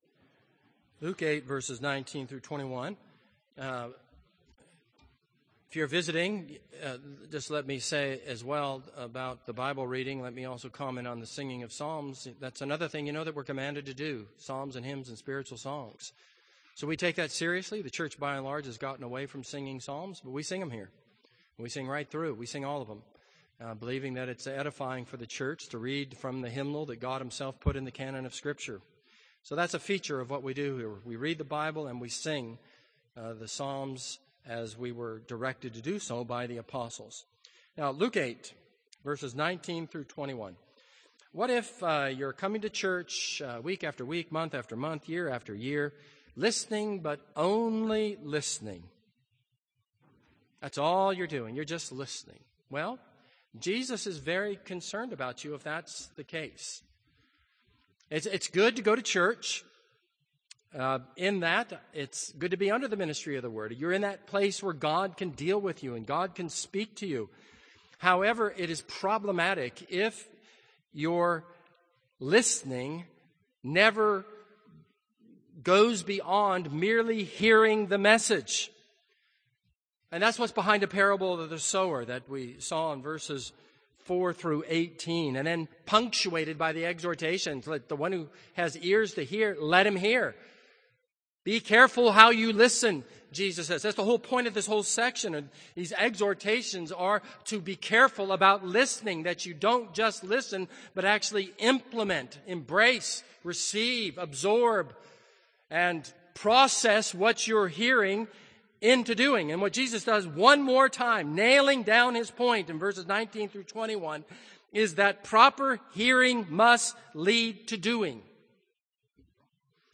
This is a sermon on Luke 8:19-21.